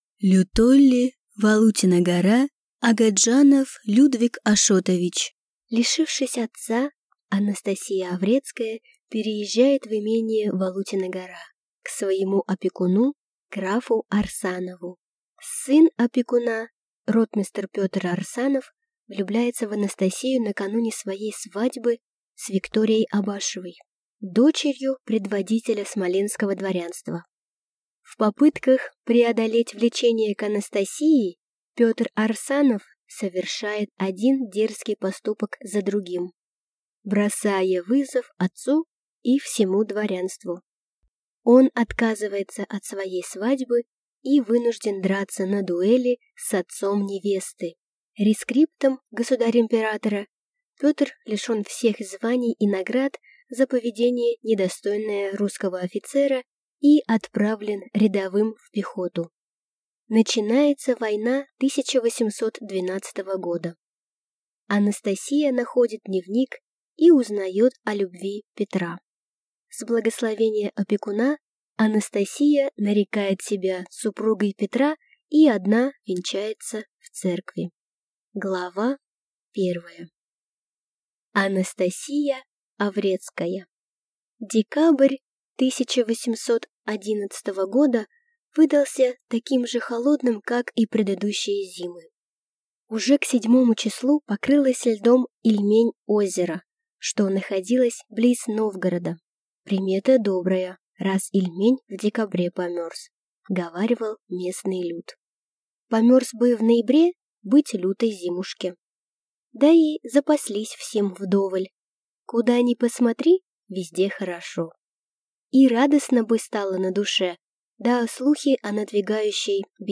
Аудиокнига Валутина гора | Библиотека аудиокниг
Прослушать и бесплатно скачать фрагмент аудиокниги